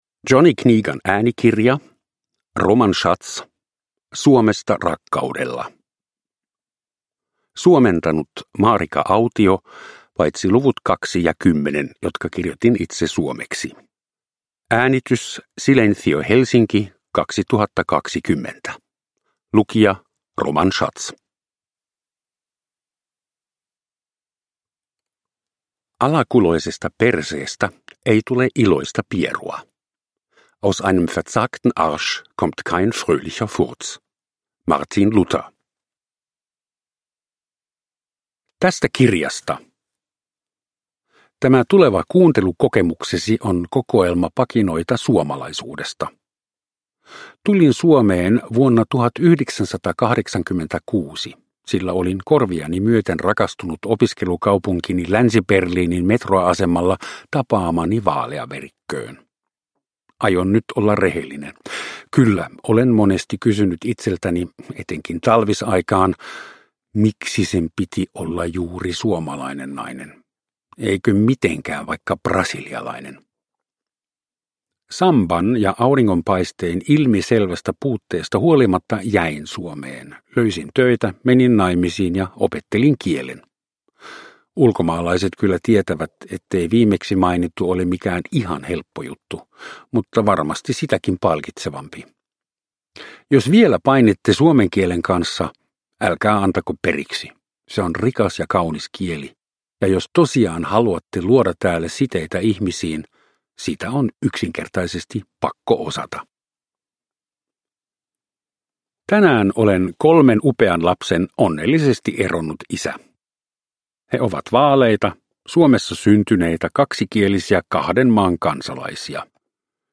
Suomesta, rakkaudella – Ljudbok
Uppläsare: Roman Schatz